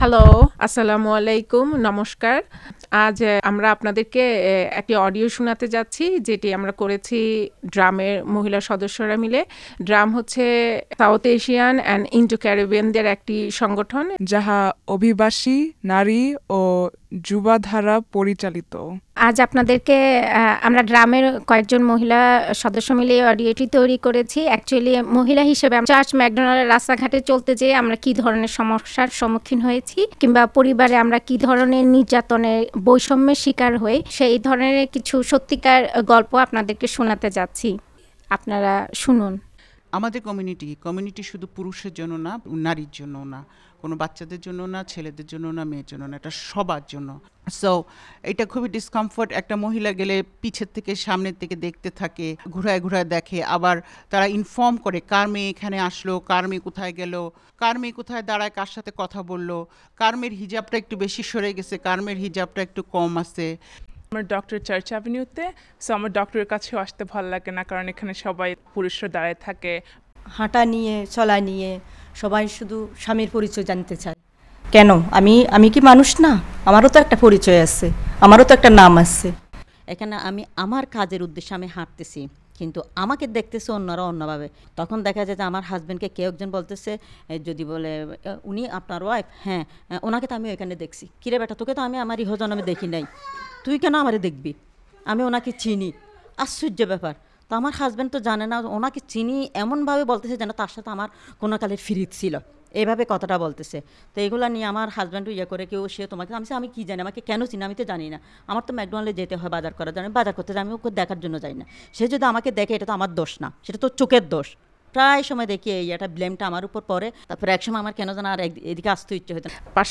The Brooklyn women and girl members made an audio sharing their experiences with gender-based oppression and violence.